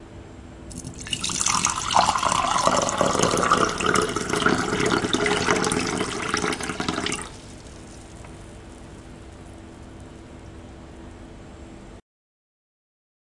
将水倒入玻璃远处
描述：水倒入一杯。
Tag: 填充 填充 玻璃 倒入 饮料 杯子 液体